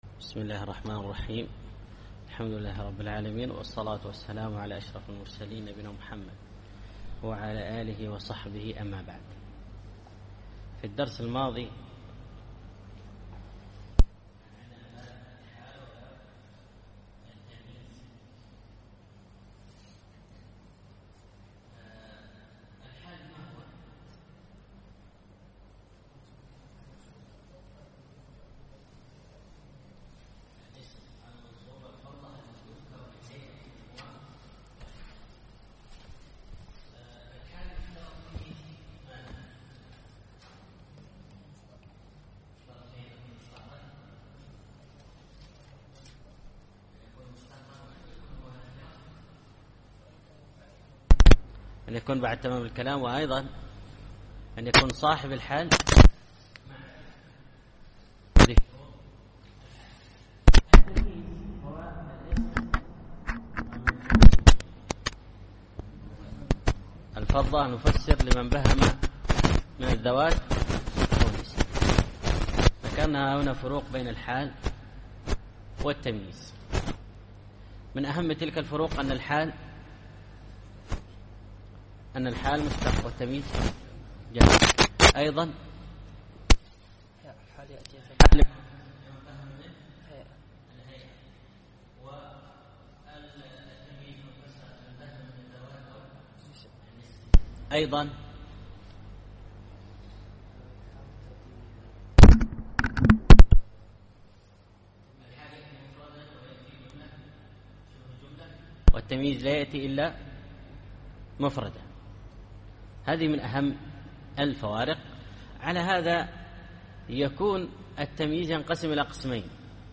الدرس الخامس عشر الأبيات 159-172